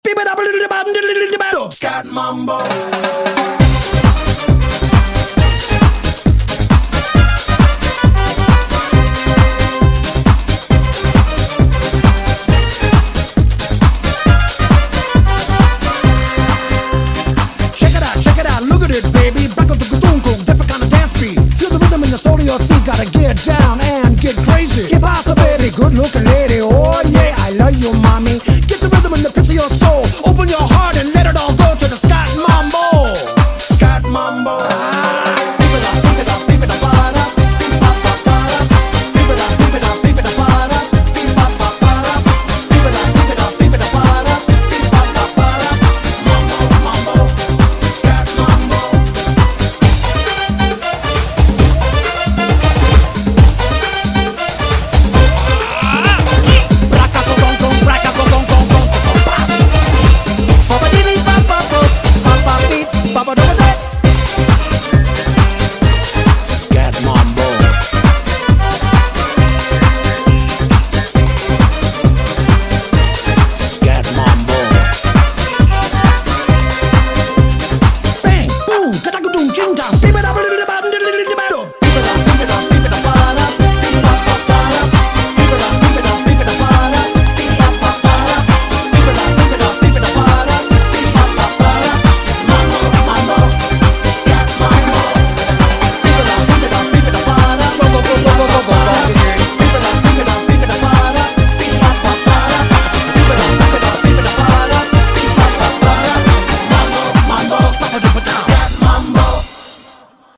BPM135--1